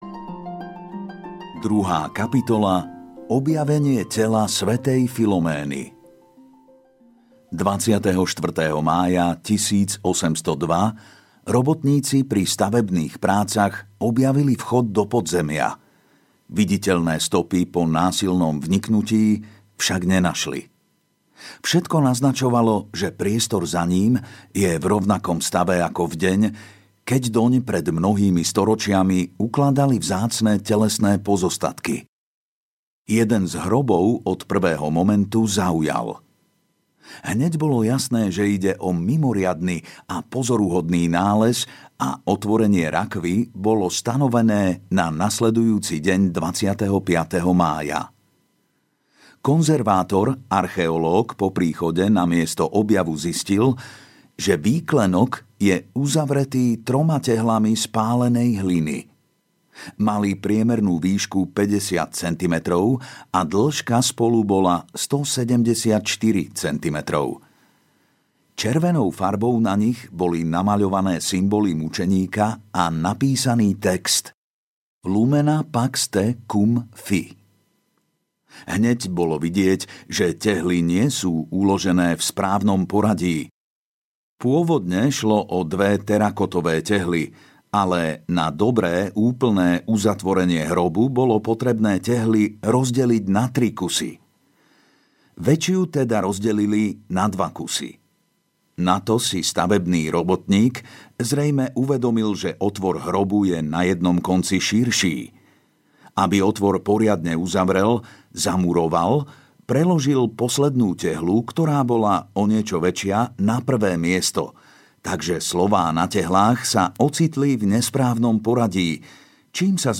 Ukázka z knihy
Nahrávky do audio podoby vznikli v bratislavskom štúdiu Rádia LUmen